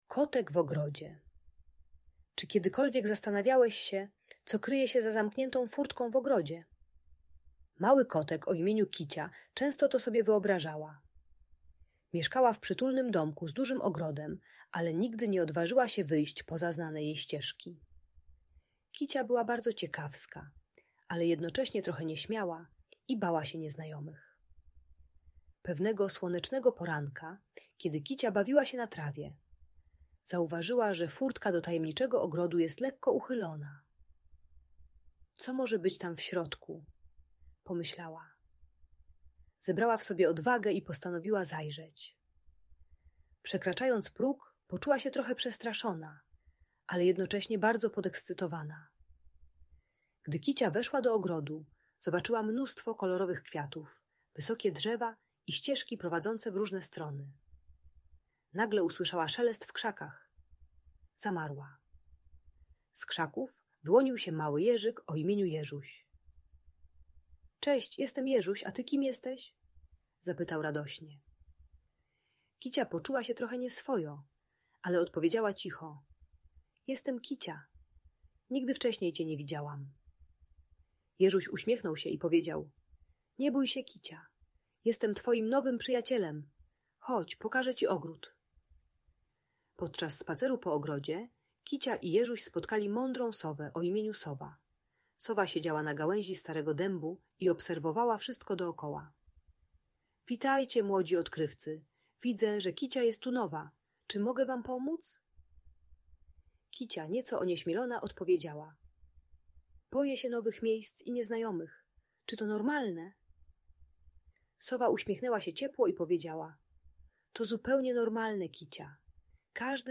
Historia Kici w Tajemniczym Ogrodzie - Audiobajka